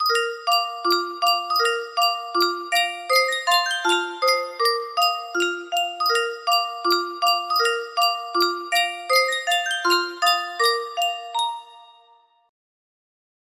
Sankyo Custom Tune Music Box - Beethoven Turkish March
Full range 60